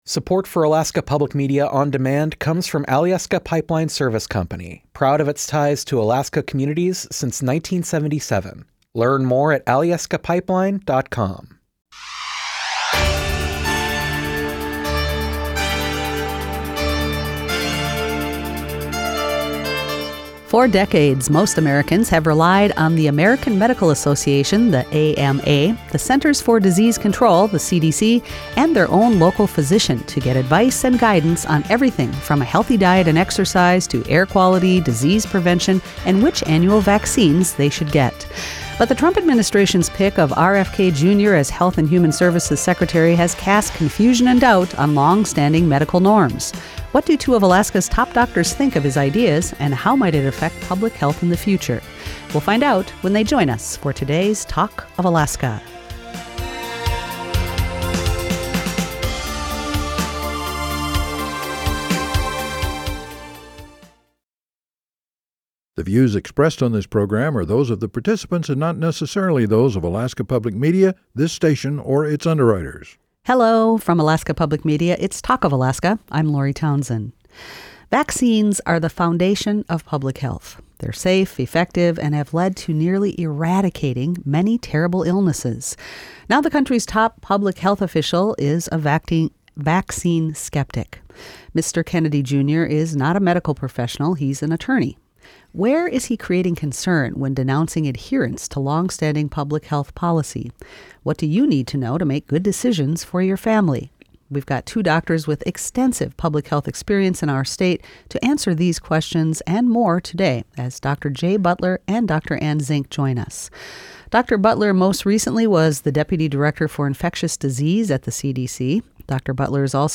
Alaska’s only statewide call-in forum for discussing the issues impacting life on the Last Frontier.